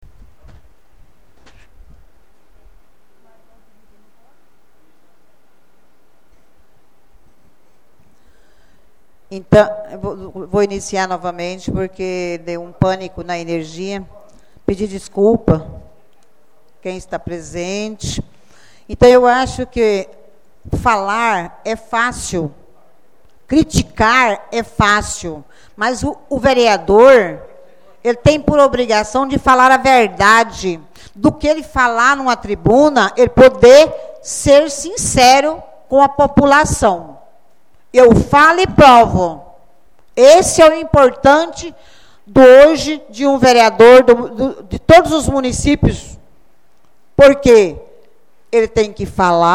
Áudio Sessão Ordinária 2º Parte